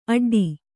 ♪ aḍḍi